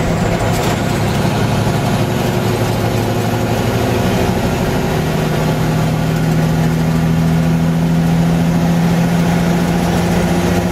fourth_cruise.wav